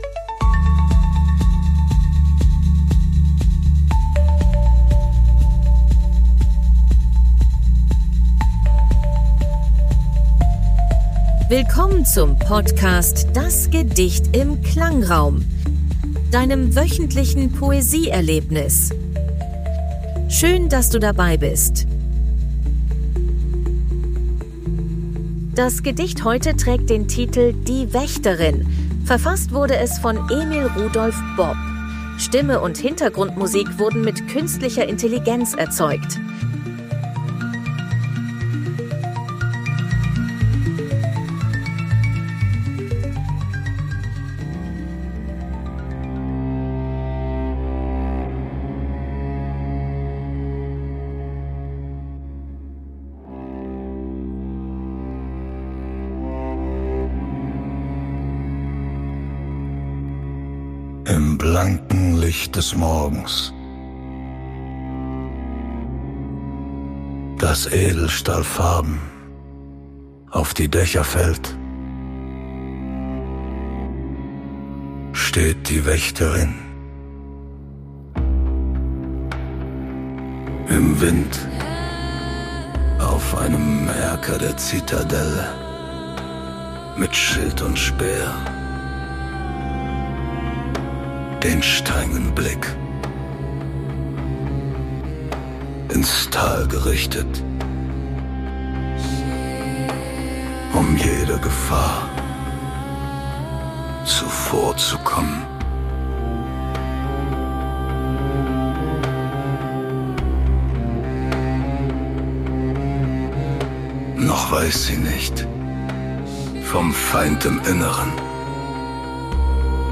Stimme und Hintergrundmusik